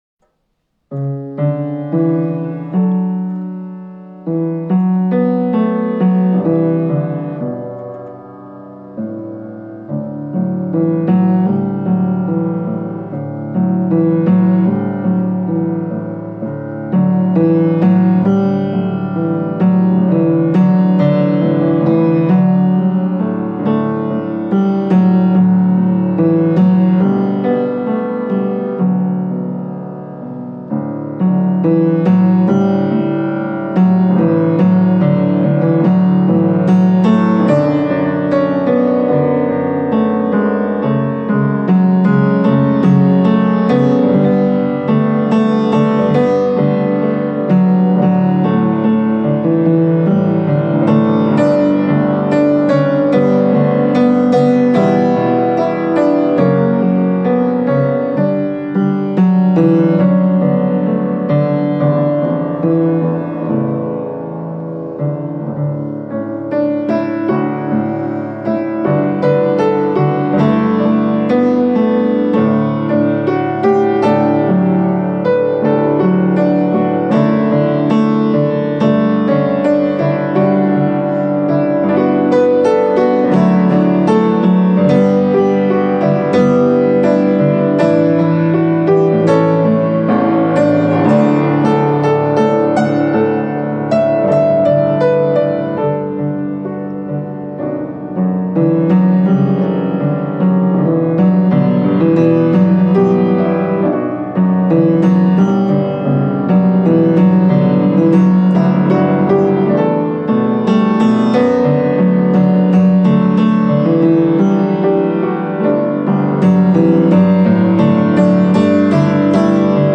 Cello and Piano
Hymn arrangement
Be-Still-It-Is-Well-Danny-Boy-Live-Audio.mp3